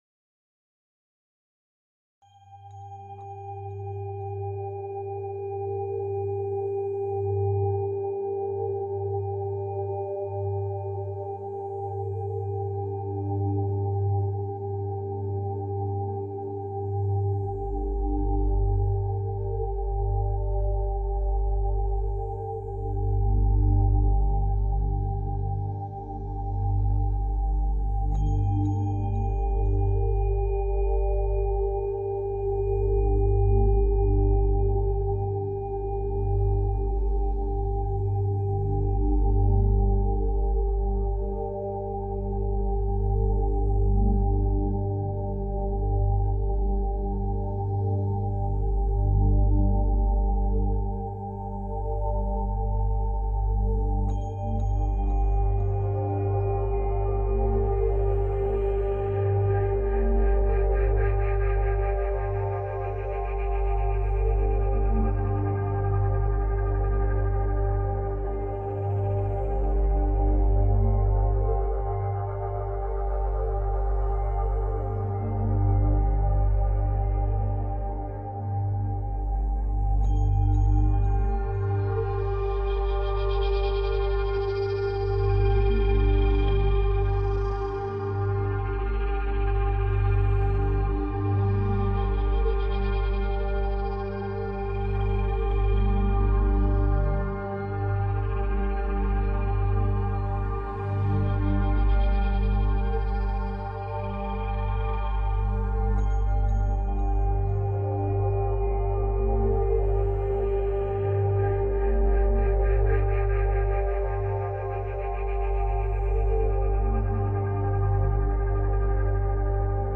UT – 396 Hz – Frecuencia para liberar el miedo y la culpabilidad